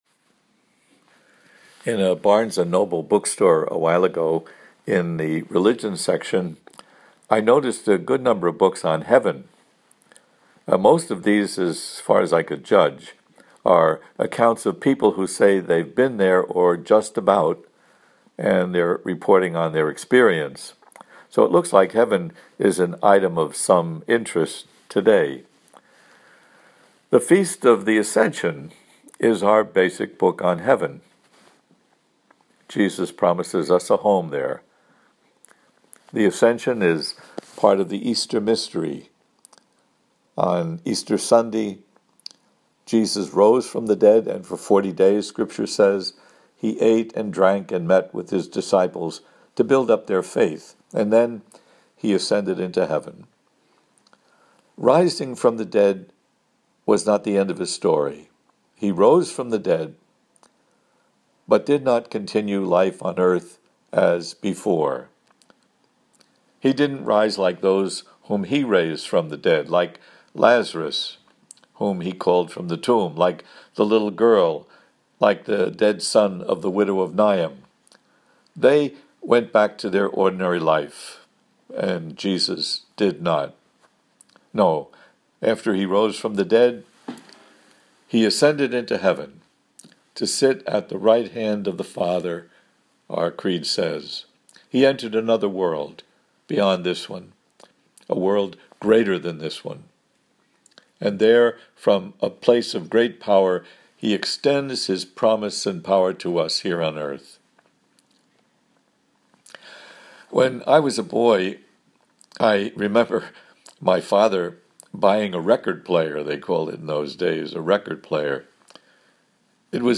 audio homily here: